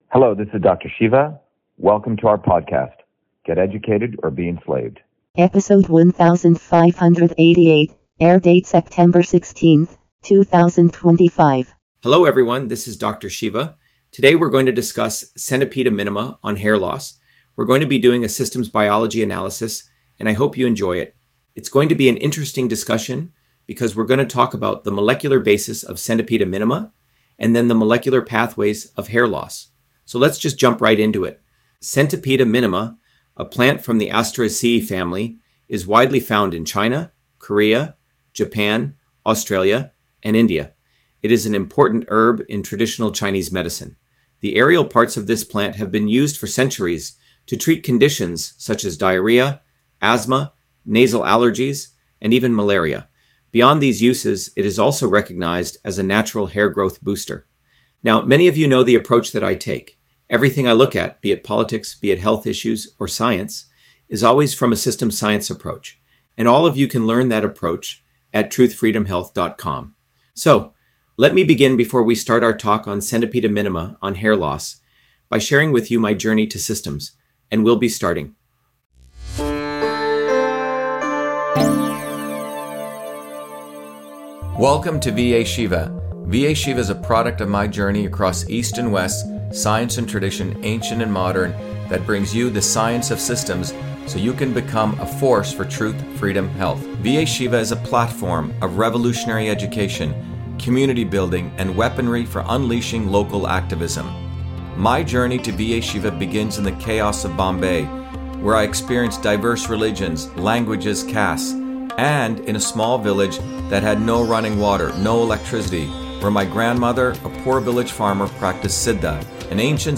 In this interview, Dr.SHIVA Ayyadurai, MIT PhD, Inventor of Email, Scientist, Engineer and Candidate for President, Talks about Centipeda minima on Hair Loss: A Whole Systems Approach